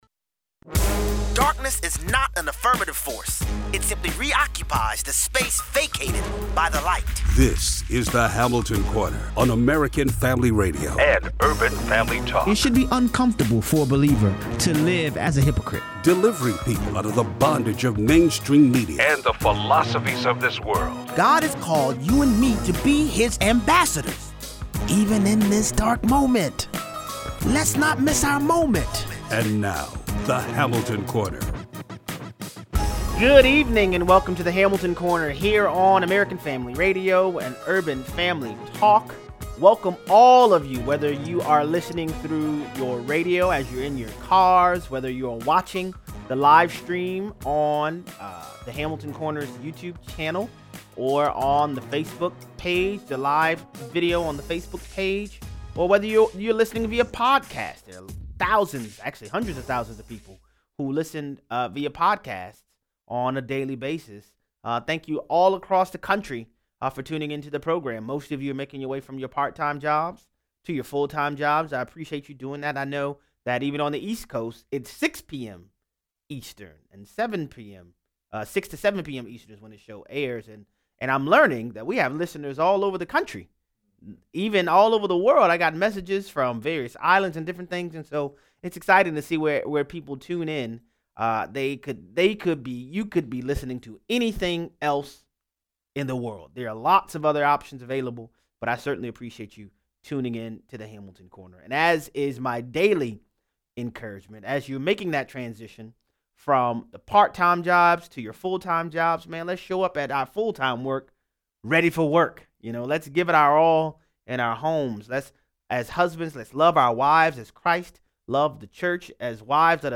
Don’t let temporal matters to turn your focus away from our eternity-driven marching orders. 0:23 - 0:40: What does Michael Cohen’s guilty plea mean legally? What are the criminal and political implications? 0:43 - 0:60: Lanny Davis goes on Megyn Kelly’s show to beg for money for Michael Cohen’s “ability” to tell the truth. Callers weigh in.